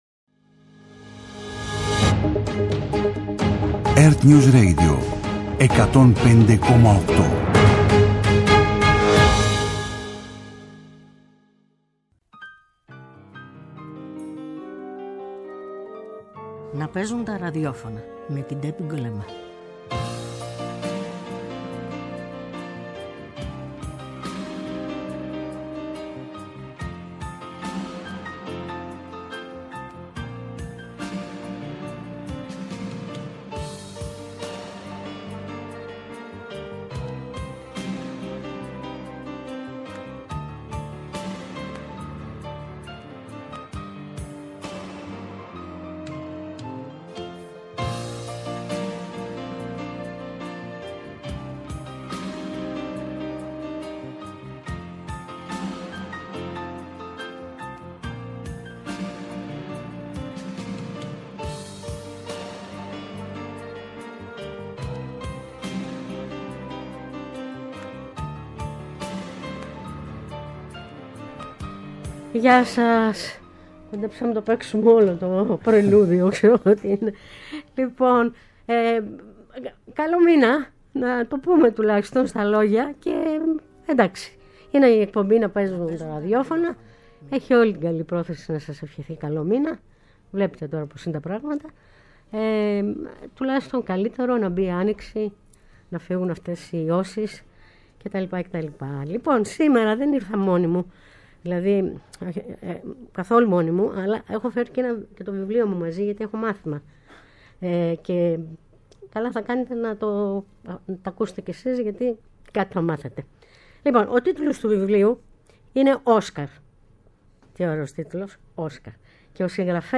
κριτικός κινηματογράφου